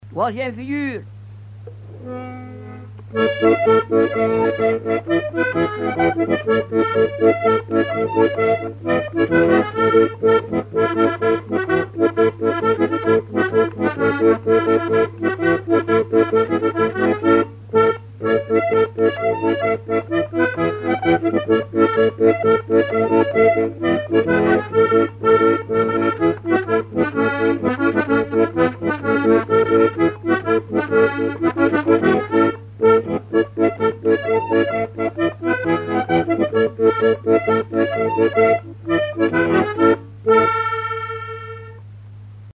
Quadrille
danse : quadrille
Pièce musicale inédite